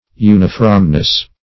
Search Result for " unifromness" : The Collaborative International Dictionary of English v.0.48: Unifromness \U"ni*from`ness\, n. The quality or state of being uniform; uniformity.